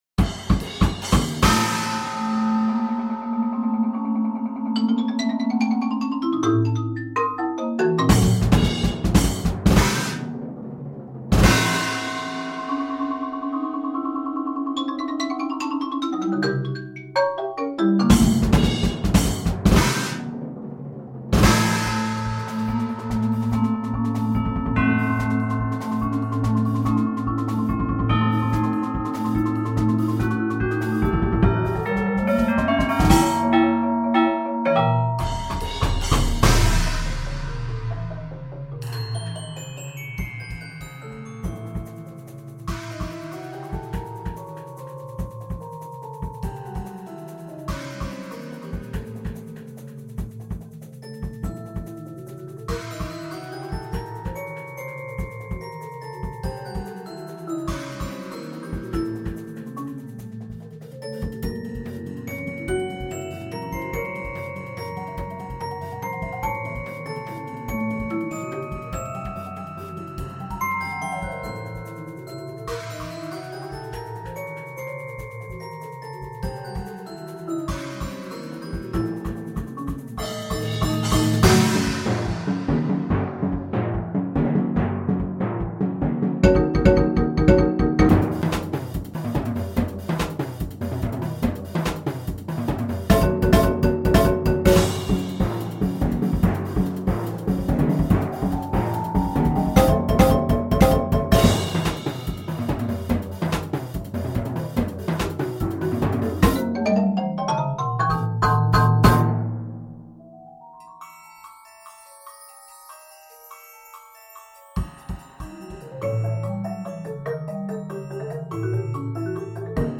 Voicing: Percussion Octet